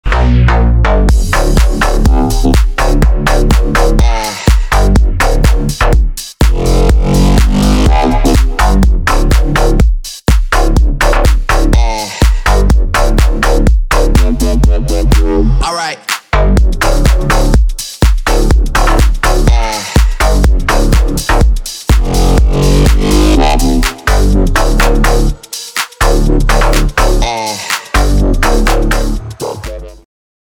ритмичные
dance
Electronic
электронная музыка
club
electro house
агрессивные